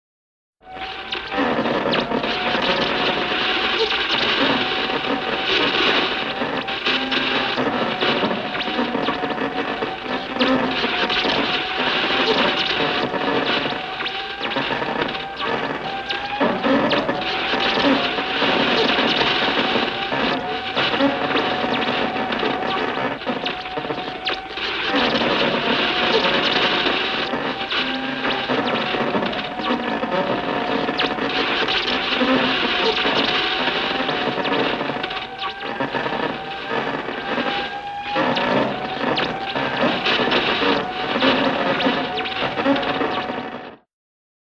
BSG FX - Large computer overloading
BSG_FX_-_Large_Computer_Overloading.wav